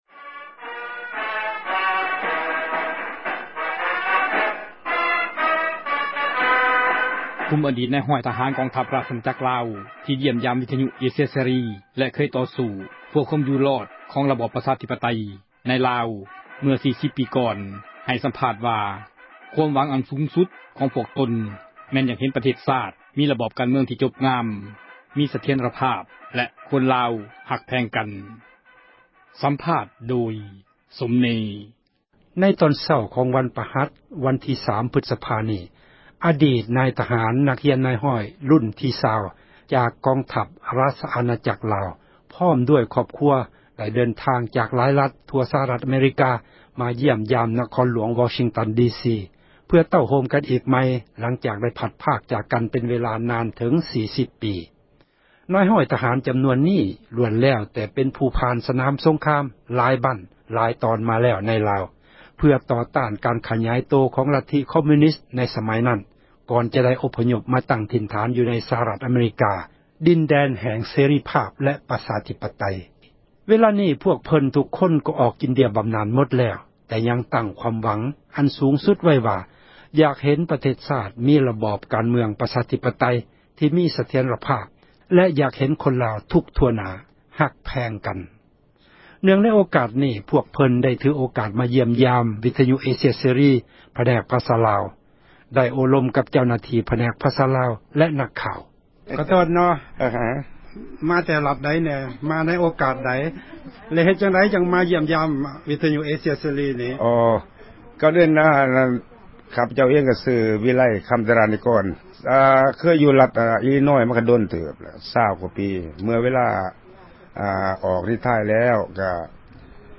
ສັມພາດກຸ່ມອະດີດ ນາຍຮ້ອຍທະຫານ